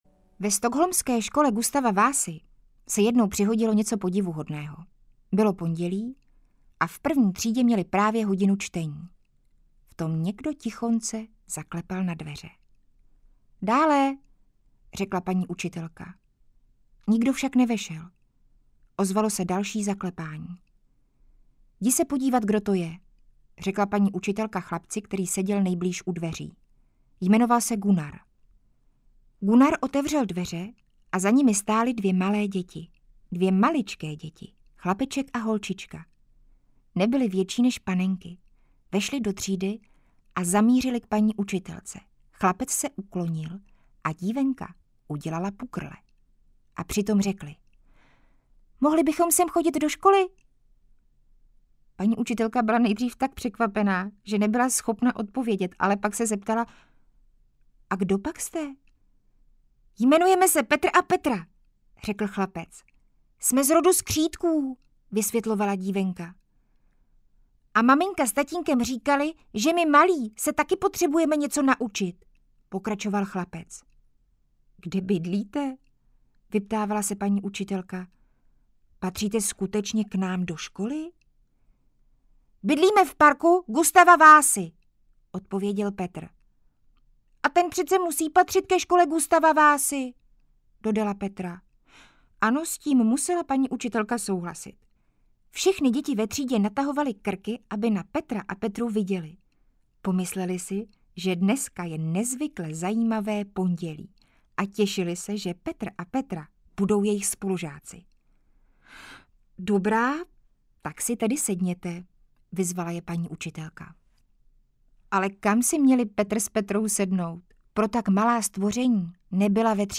Audiobook
Read: Linda Rybová